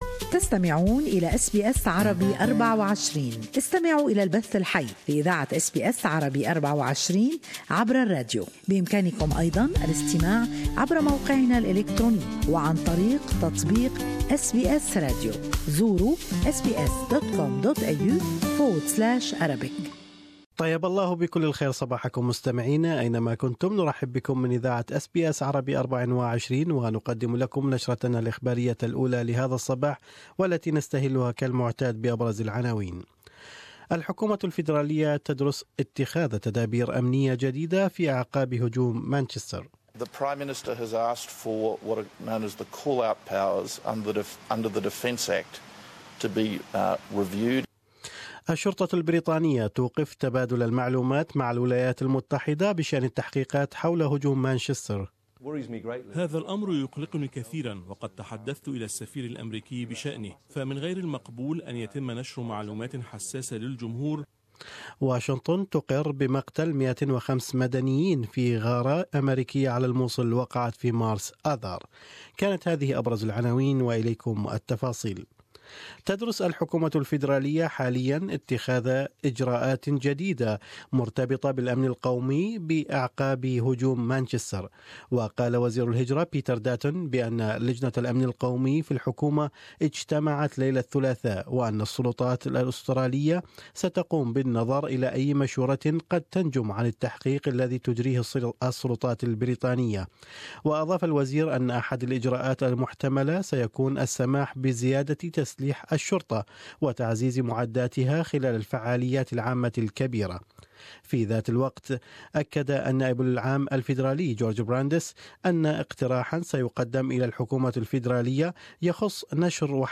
Latest Australian and world news in morning news bulletin.